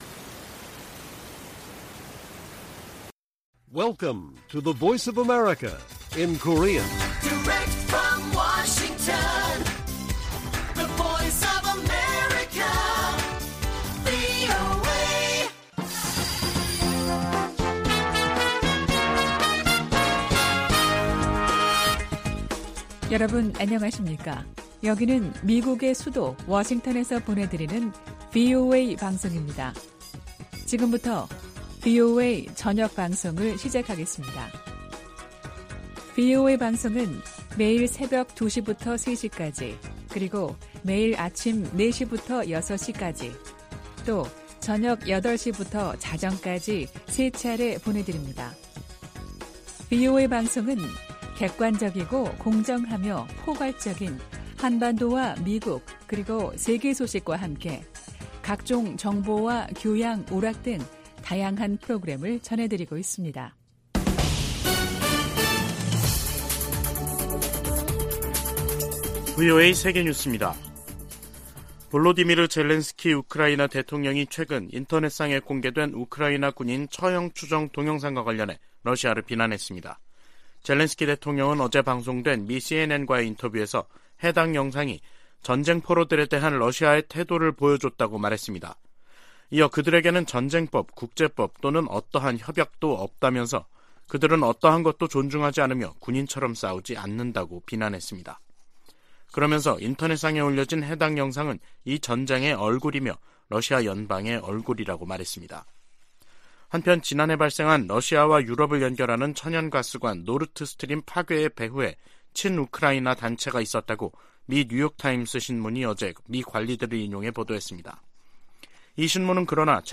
VOA 한국어 간판 뉴스 프로그램 '뉴스 투데이', 2023년 3월 8일 1부 방송입니다. 백악관은 윤석열 한국 대통령이 다음 달 26일 미국을 국빈 방문한다고 밝혔습니다. 미국 사이버사령관이 미국 정치에 개입하려 시도하는 상위 4개국으로 북한과 중국, 러시아, 이란을 꼽았습니다. 한국은 전시작전통제권 전환을 위한 일부 역량을 키웠지만 연합방위를 주도할 능력을 입증해야 한다고 전 주한미군사령관이 지적했습니다.